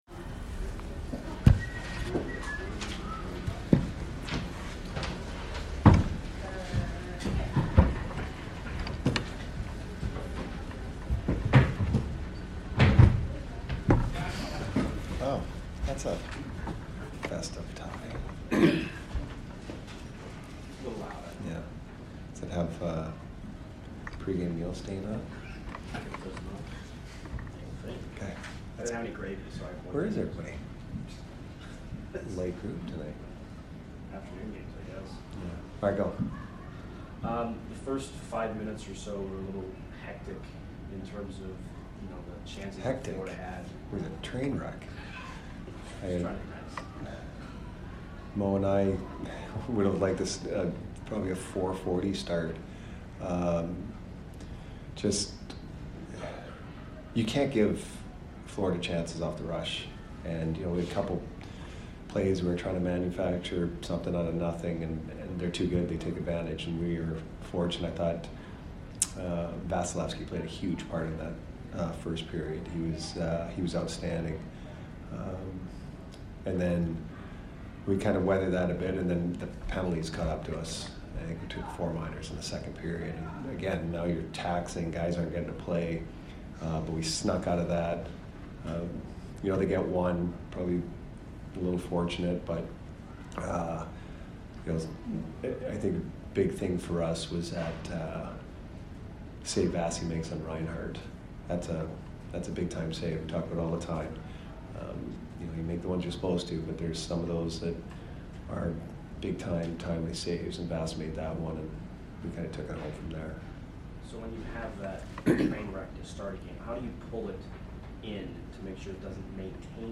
Head Coach Jon Cooper Post Game 12/10/22 vs FLA